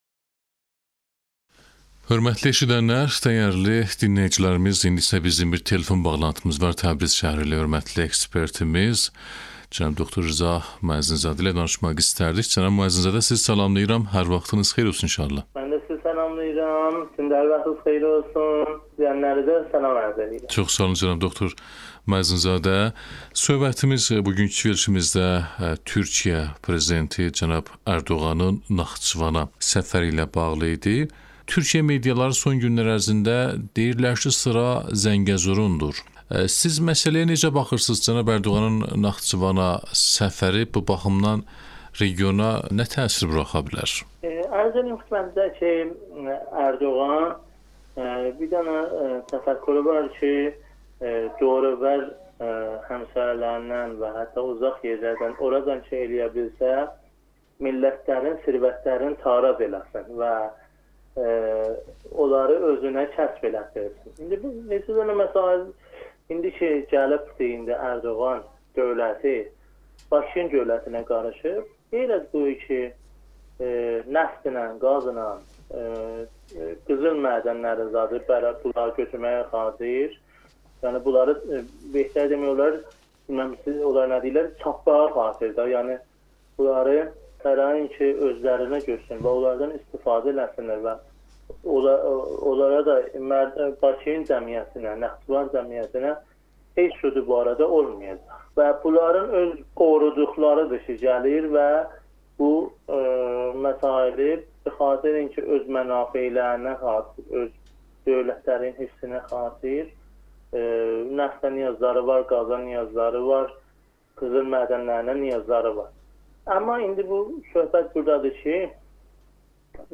müsahibə edib